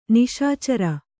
♪ niśa cara